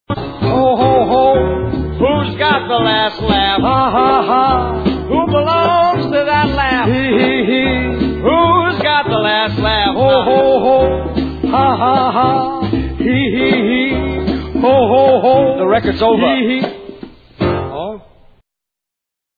the LP's session was relaxed and intimate.
"Ho Ho Ho's" and "Ha Ha Ha's"(Audio)